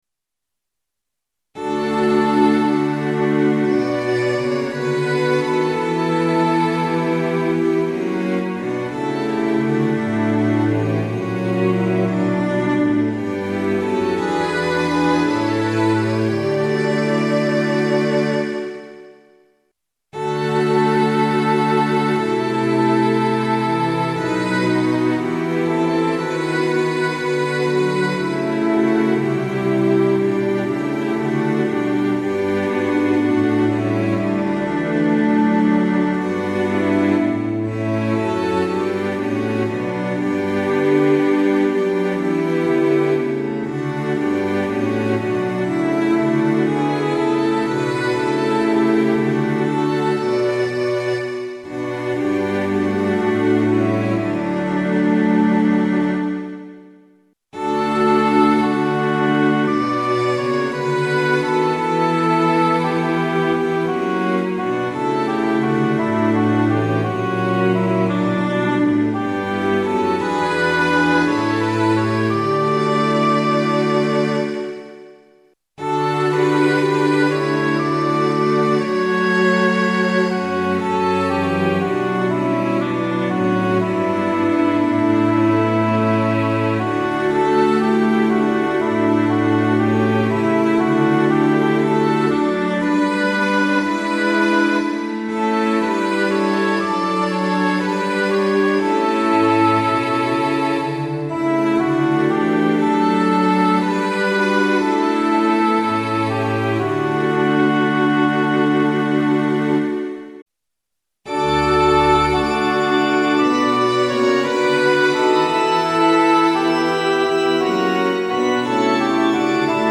混声四部合唱+器楽 Four-part mixed chorus with Instruments
0.9.8 D Choir(S,A,T,B)
Sample Sound ：参考音源 - 様々な音色の組み合わせによる
DL Fl+Str Fl+Str Ob+Str Ob-Str Ob-Str-Org